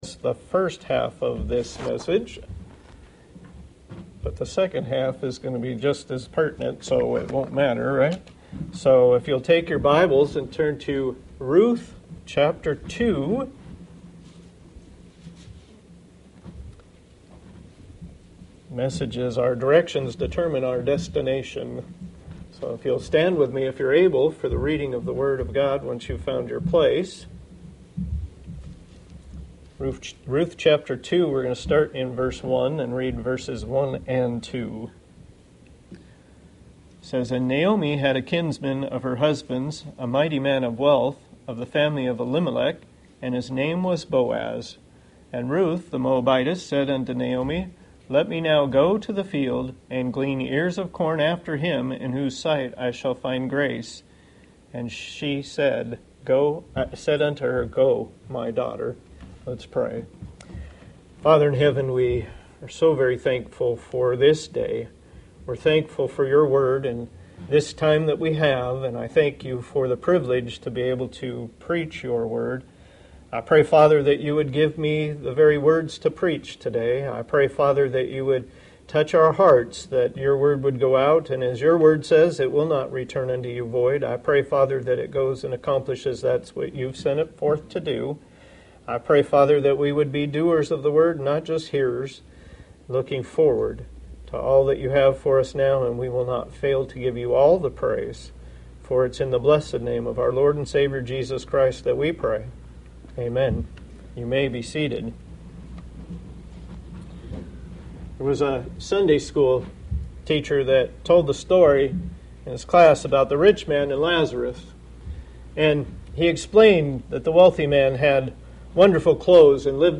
Passage: Ruth 2:1-2 Service Type: Sunday Morning